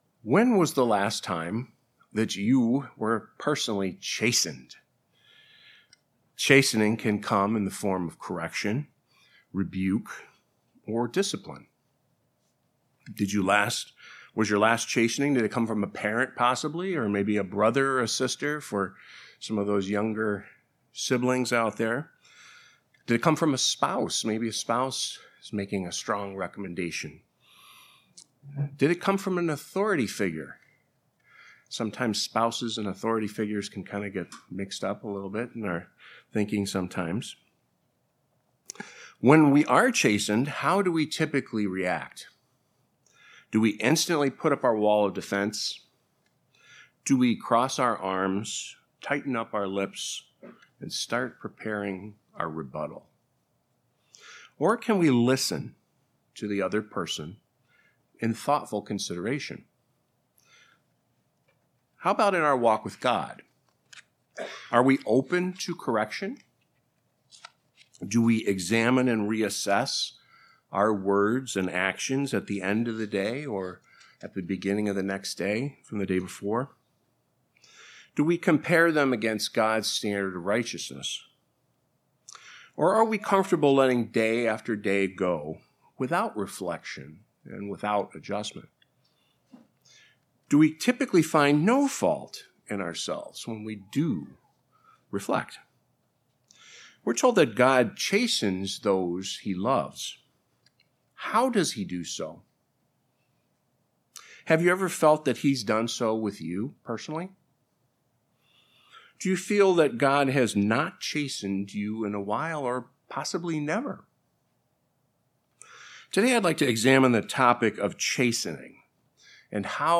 Given in Beloit, WI Chicago, IL